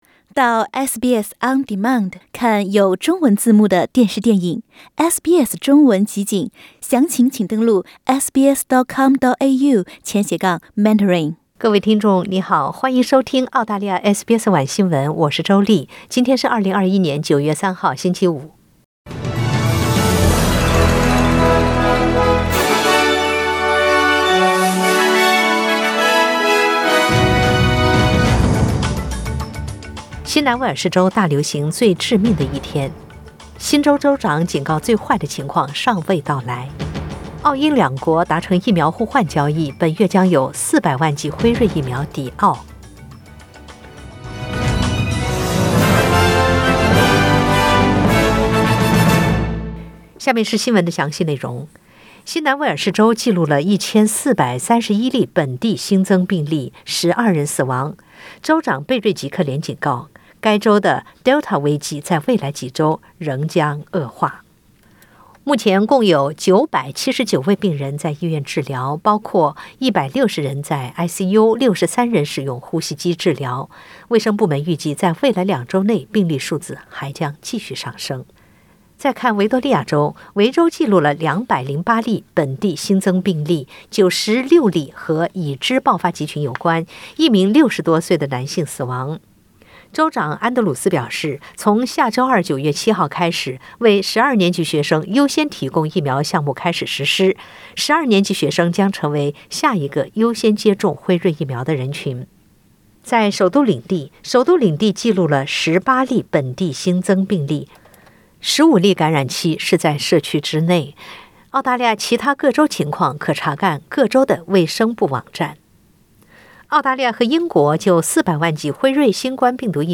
SBS 晚新聞 (2021/9/3)